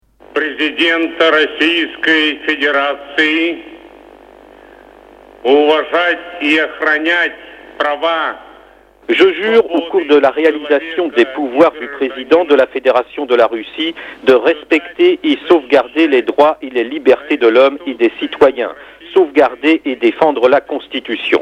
Mitterrand, François : Discours à Sarajevo (Podcast)